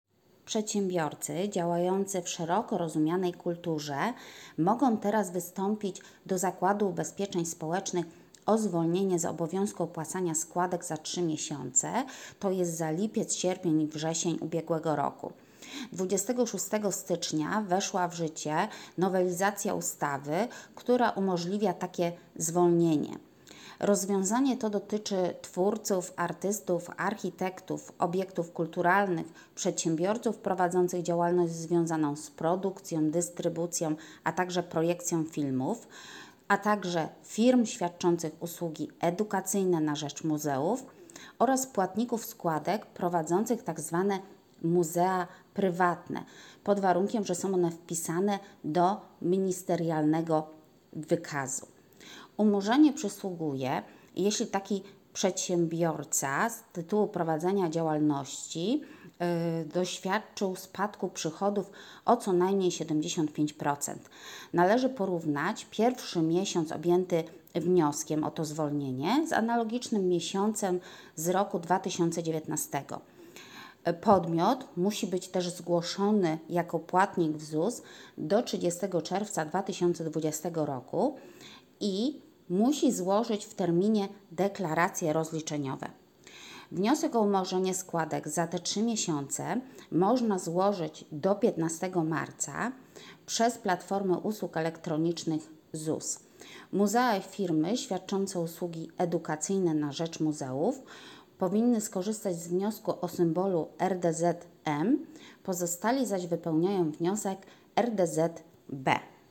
Nagranie głosowe przedstawiające treść komunikatu prasowego